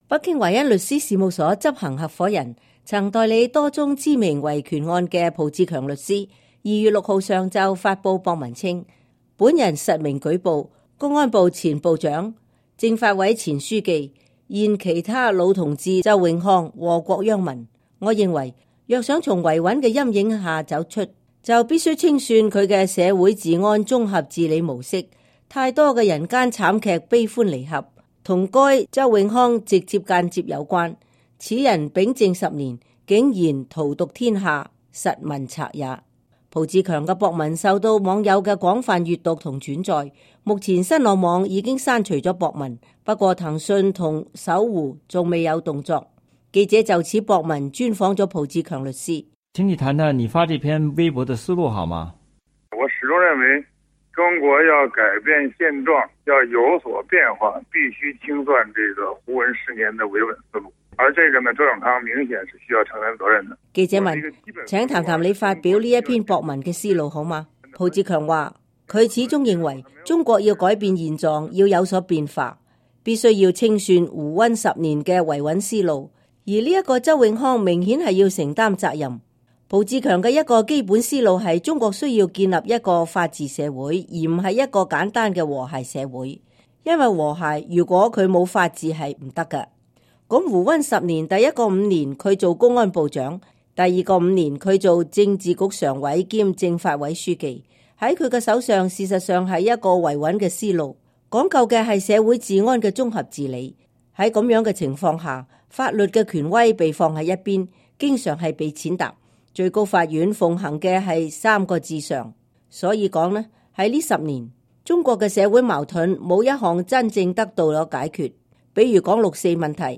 美國之音專訪浦志強談微博實名舉報周永康